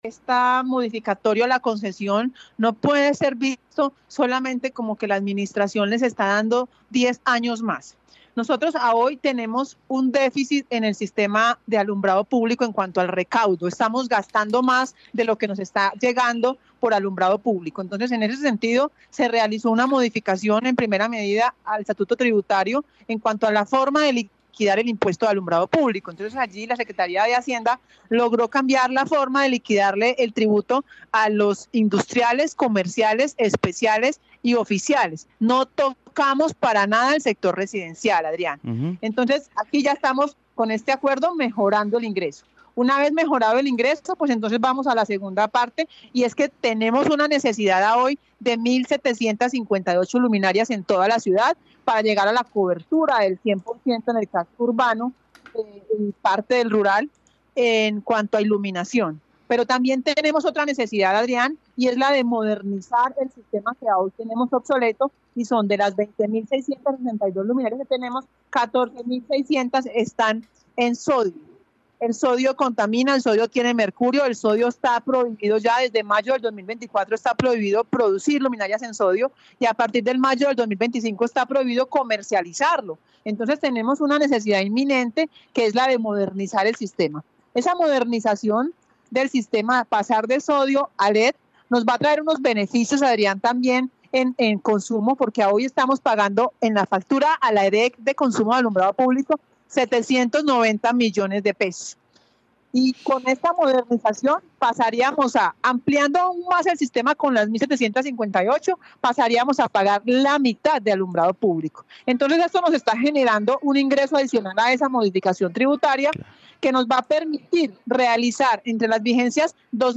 Claudia Arenas secretaria de Infraestructura de Armenia
La funcionaria habló con Caracol Radio Armenia sobre la ampliación de 10 años más de la concesión del alumbrado público que le aprobado el concejo a la alcaldía municipal